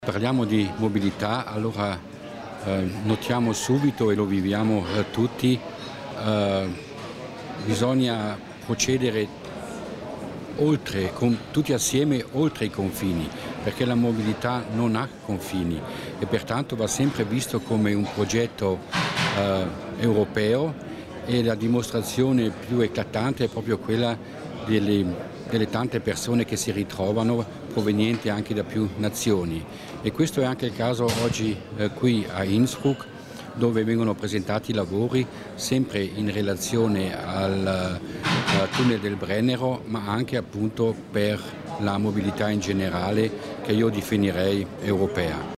L'Assessore Mussner spiega l'importanza del futuro del mobilità in ambito Euregio